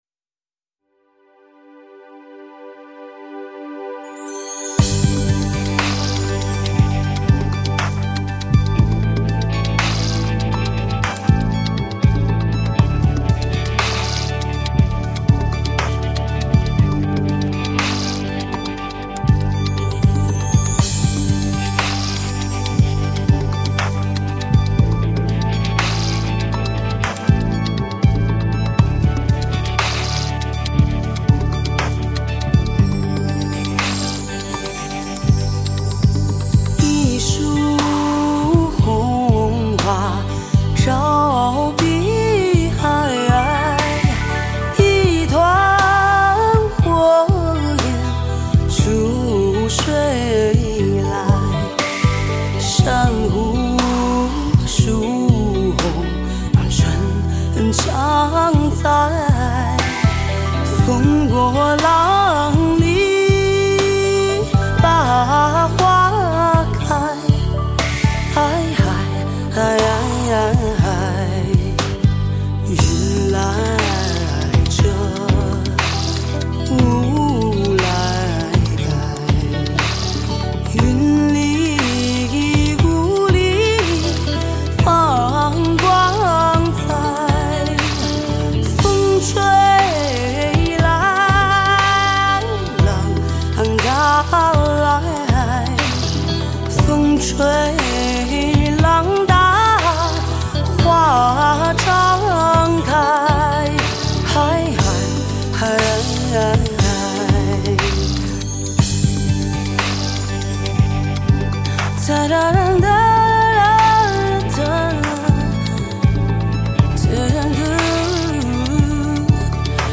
精选60首经典革命红歌 勾起属于你的记忆